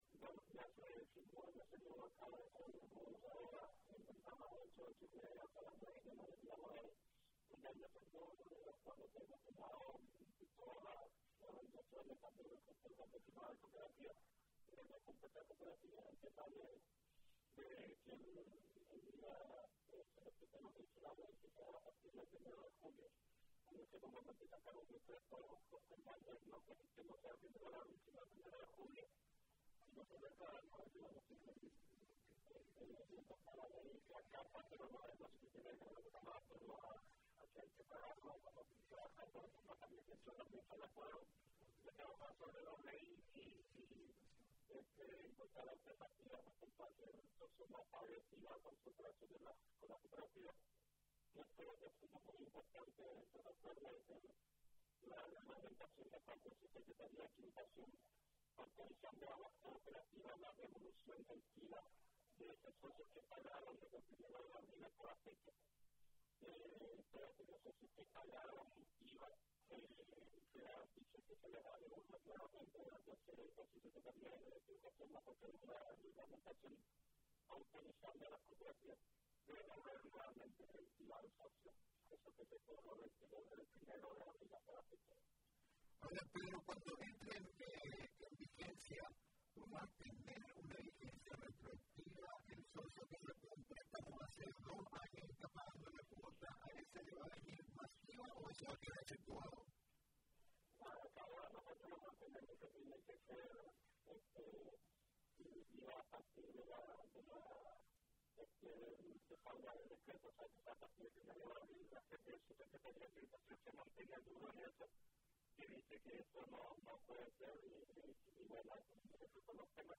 en contacto con la R800AM mencionó que permanecerán movilizados y que ello no significa solamente marchar